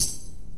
drum-slidertick.ogg